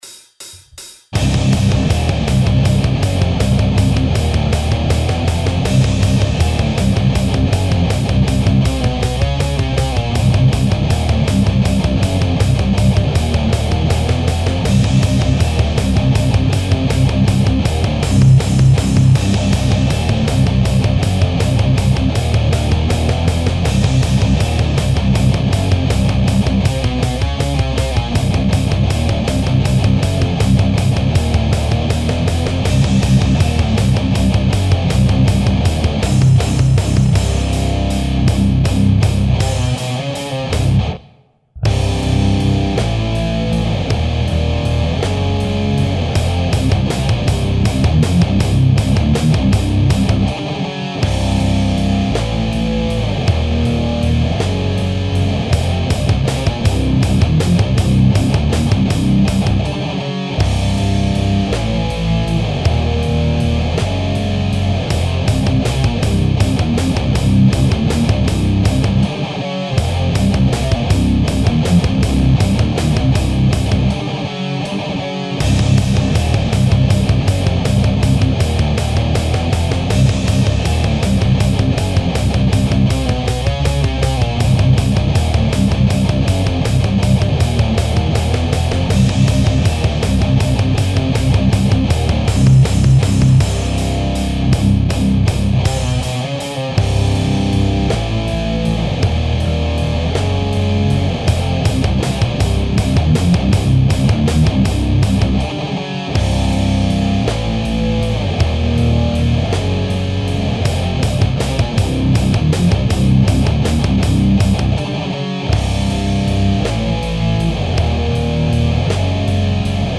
II really like this song because it has a classic metal feel to it but with all kinds of oddities (main riff is in 3/4 and the verse riff is in 3/4 and 13/8 of all things, I know this means nothing to most of you) The middle section on this would be a lot of fun to play live.